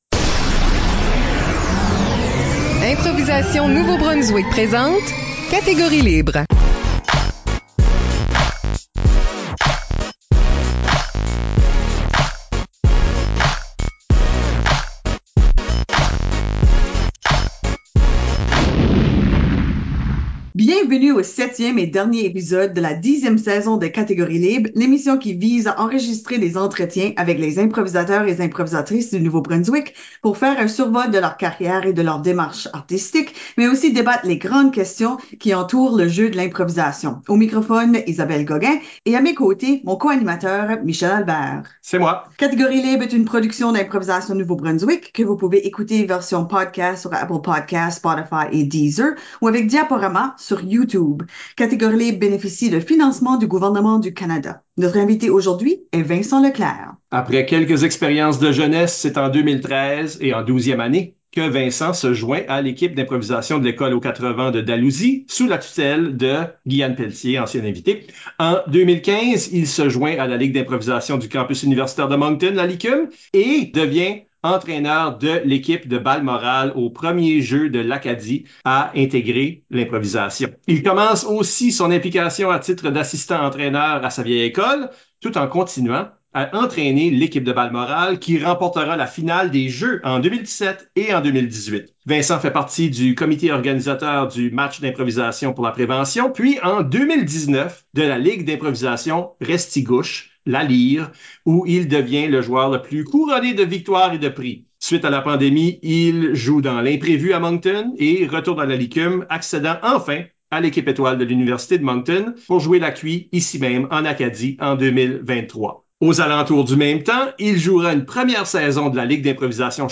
Entretiens avec des improvisateurs et improvisatrices du Nouveau-Brunswick